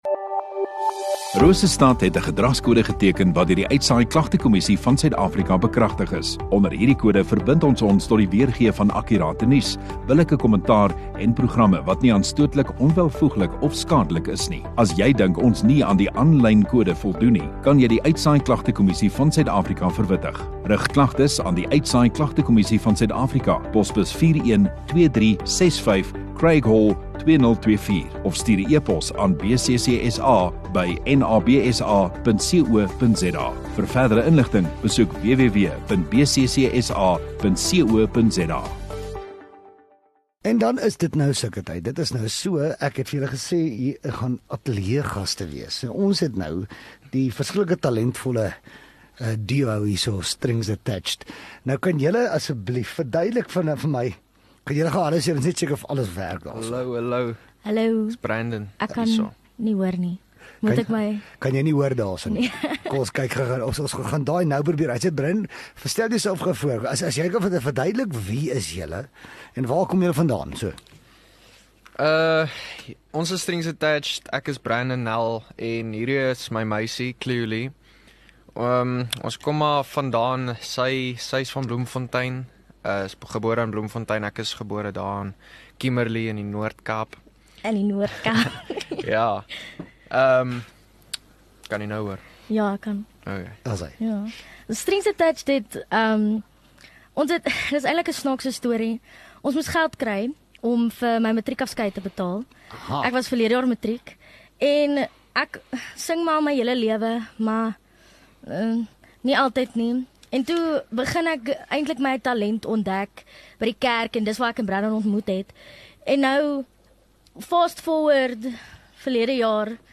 Radio Rosestad View Promo Continue Radio Rosestad Install Kunstenaar Onderhoude 30 Jun Kunstenaar - Strings Attached 21 MIN Download (10.2 MB) AF SOUTH AFRICA 00:00 Playback speed Skip backwards 15 seconds